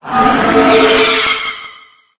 Cri_0887_EB.ogg